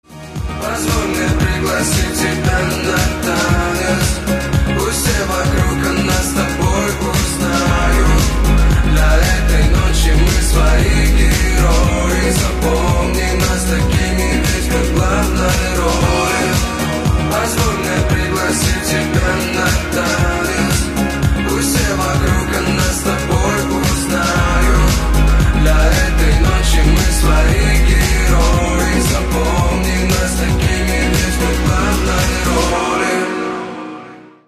• Качество: 320, Stereo
поп
мужской вокал
dance